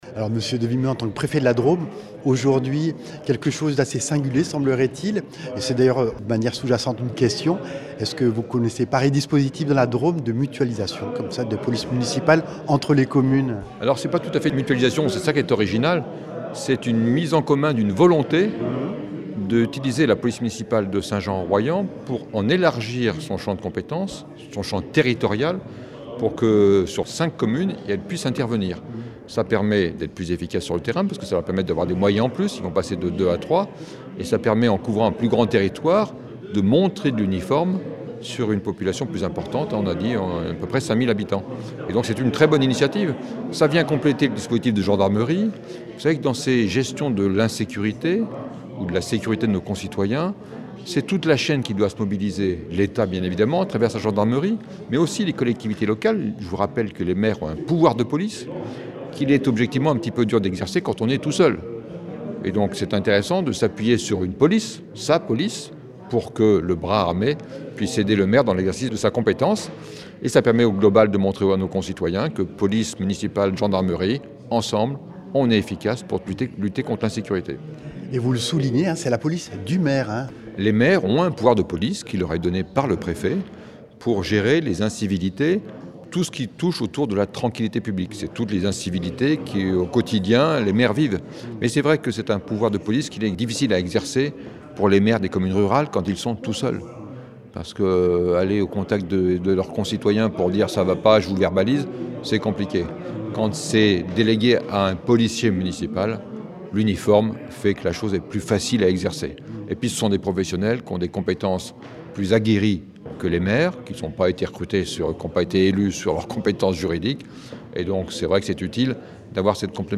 Itw lors de la signature de la convention en mairie de St Jean en R. de Thierry Devimeux, préfet de la Drôme ; Christian Morin, maire de St Jean en Royans ; Jean-Jacque Dallon, maire d’Oriol en R et Rémi Saudax, maire de St Nazaire en R. ainsi que 2 policiers municipaux.